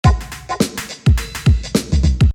You can see an example pattern with 7 sounds (one in each row) and 16 steps (one in each column) below.
In the example above, the drum machine will trigger the sound for the bass drum at steps 0, 7, and 10.
drums.mp3